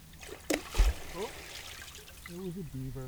The spectrogram shows the sound both underwater (top) and simultaneously in air (bottom).
Listen to a beaver tail slap. The sound of the slap underwater can be heard in your left ear, while the sound above water in your right ear. Note, sound levels are not comparable.
Castor canadensis tail slap 1Jun17.wav